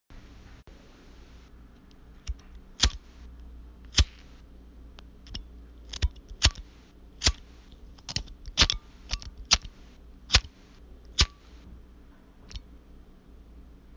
Match Matchstick
描述：Using a matchbox.
标签： match matchstick spark candle lighter ignition flame matchbox cigarette burn light fire burning ignite
声道立体声